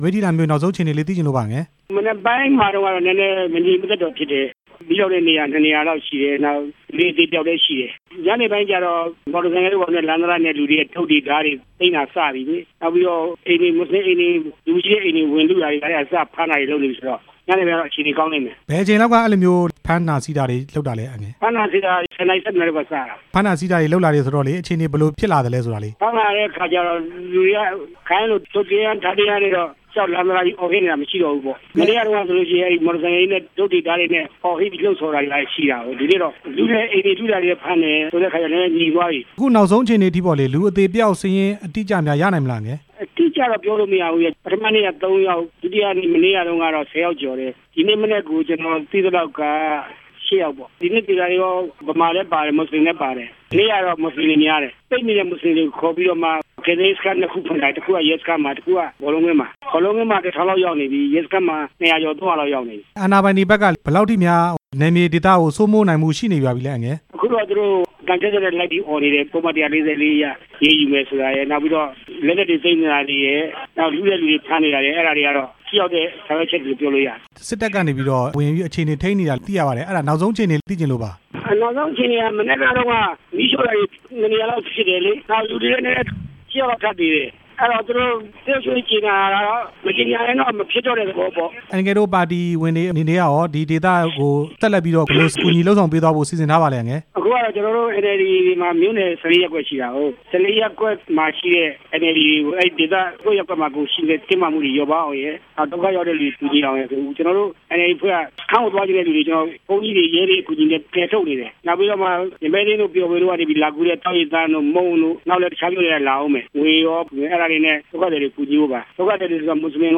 မိတ္ထီလာ ပြည်သူ့လွှတ်တော် ကိုယ်စားလှယ် ဦးဝင်းထိန်နဲ့ မေးမြန်းချက်